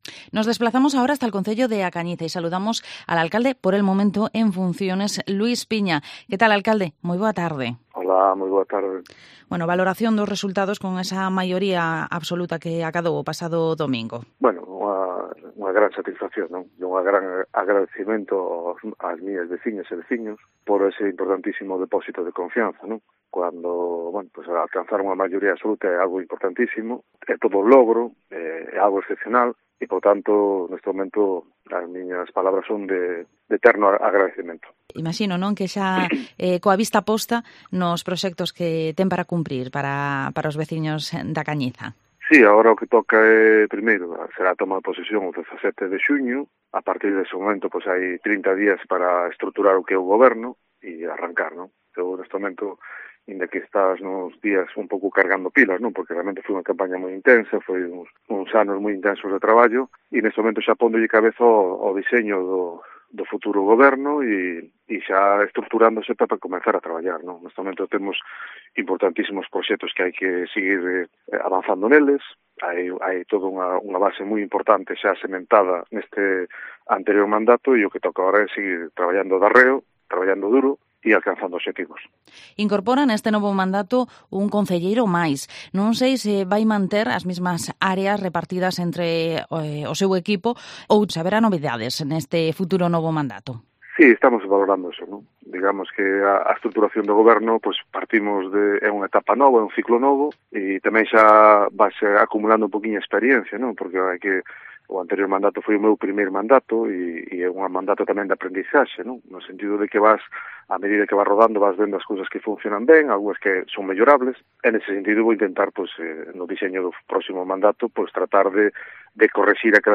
Entrevista al Alcalde en funciones de A Cañiza, Luis Piña